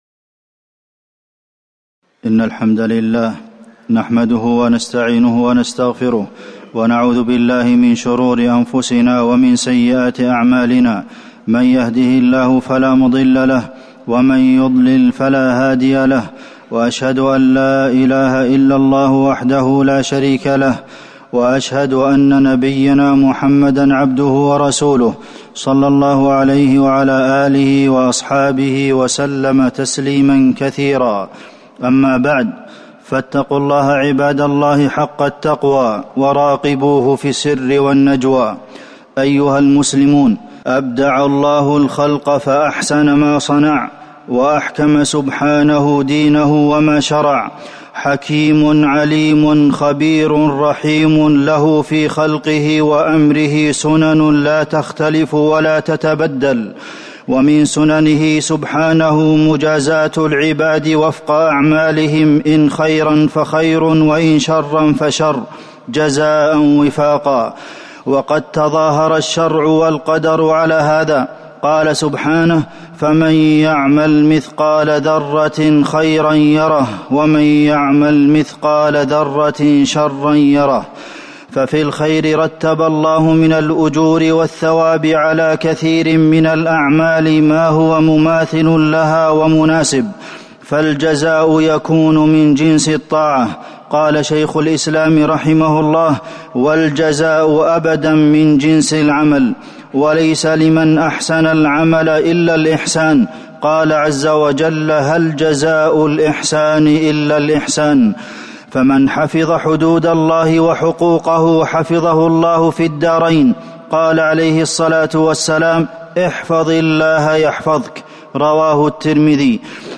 تاريخ النشر ١٥ جمادى الأولى ١٤٤١ هـ المكان: المسجد النبوي الشيخ: فضيلة الشيخ د. عبدالمحسن بن محمد القاسم فضيلة الشيخ د. عبدالمحسن بن محمد القاسم جزاءً وفاقا